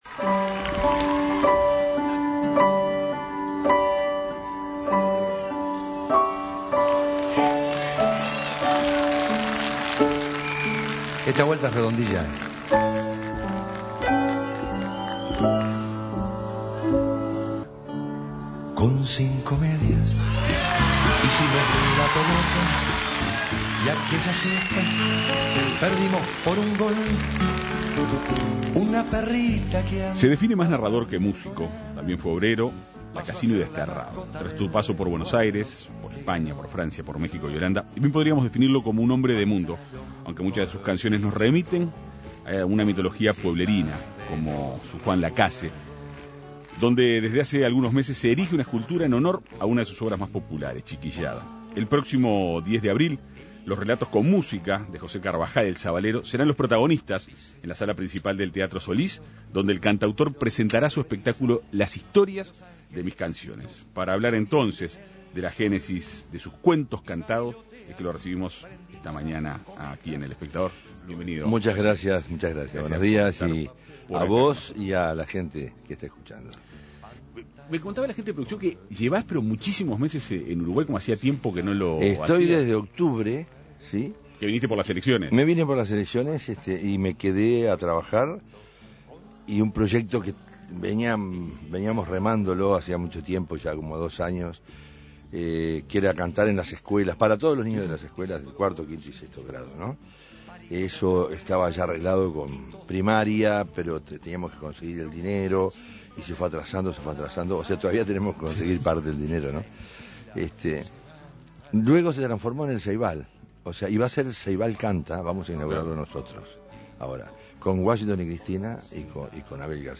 El próximo 10 de abril, los relatos con música de José Carbajal "El Sabalero" serán los protagonistas en la sala principal del Teatro Solís, donde el cantautor presentará su espectáculo Las historias de mis canciones. Para conocer detalles del show, En Perspectiva Segunda Mañana dialogó con el cantautor.